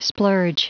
Prononciation du mot splurge en anglais (fichier audio)
Prononciation du mot : splurge